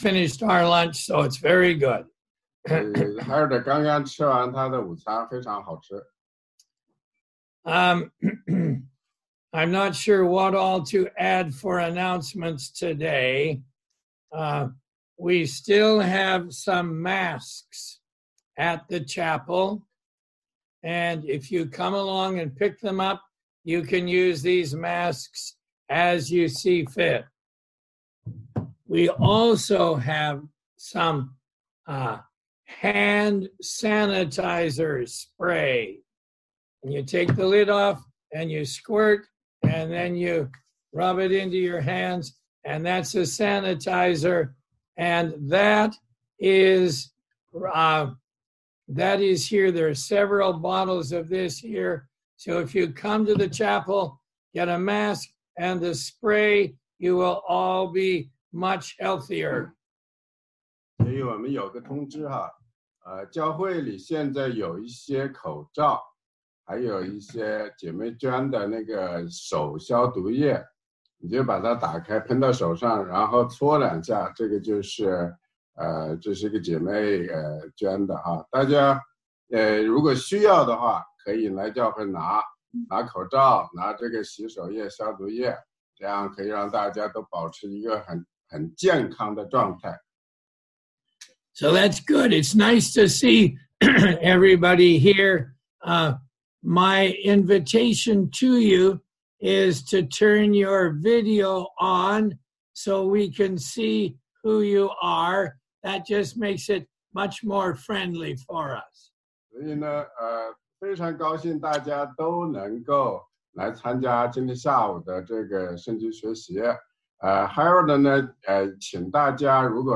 16街讲道录音 - 哥林多前书12章24节-13章7节：爱的颂歌